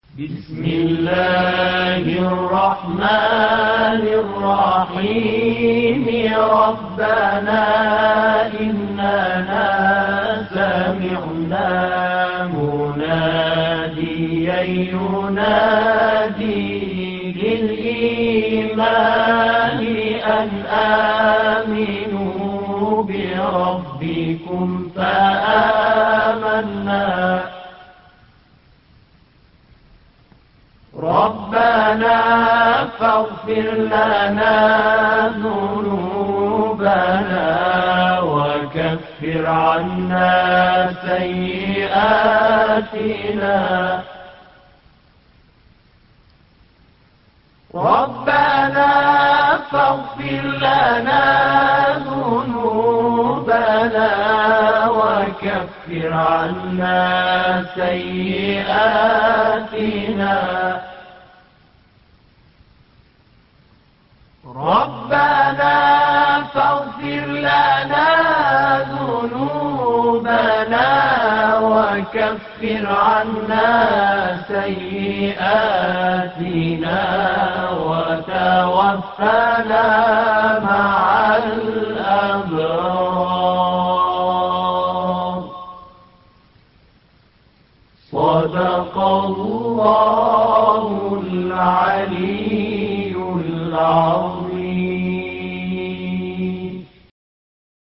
ربنا ـ مقام الصبا - لحفظ الملف في مجلد خاص اضغط بالزر الأيمن هنا ثم اختر (حفظ الهدف باسم - Save Target As) واختر المكان المناسب